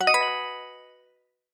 En Güzel 2026 Bildirim Sesleri İndir - Dijital Eşik